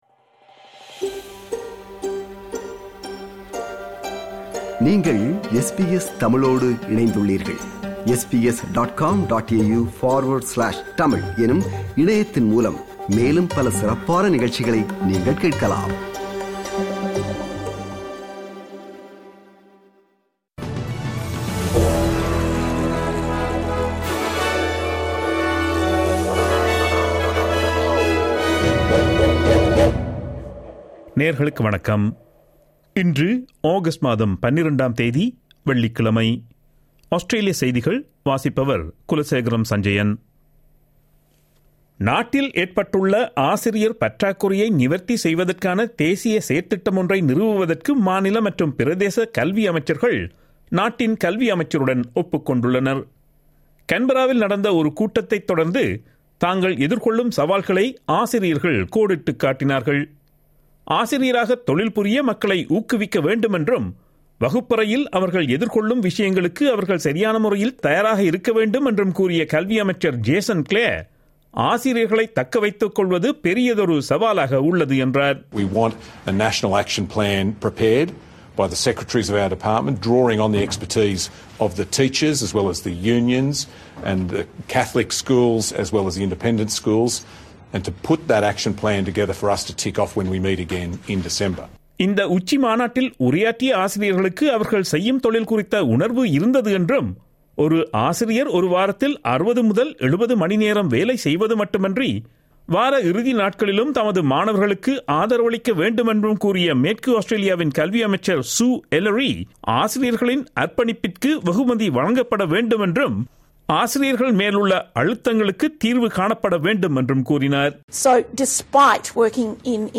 Australian news bulletin for Friday 05 August 2022.